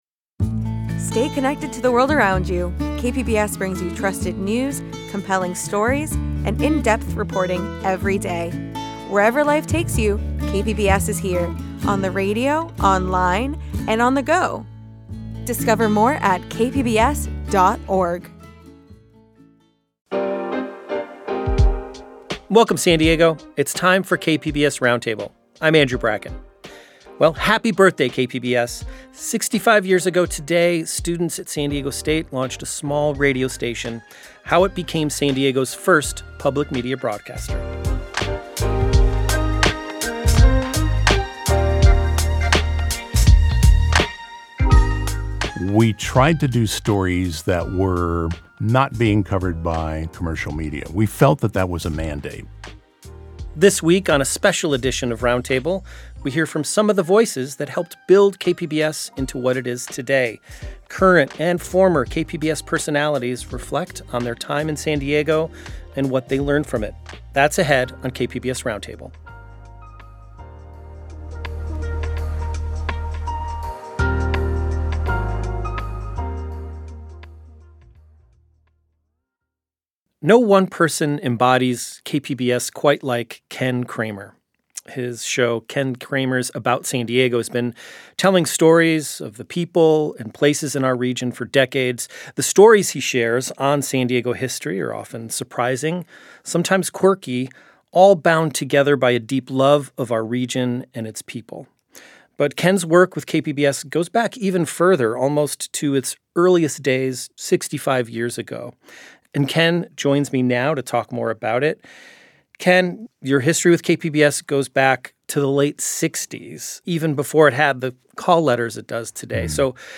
KPBS Roundtable airs each Friday at 12 p.m. and again Sunday at 6 a.m. across San Diego on 89.5 FM and Imperial County on 97.7 FM.